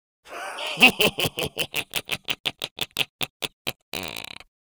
Sinister Laughs Male 01
Sinister Laughs Male 01.wav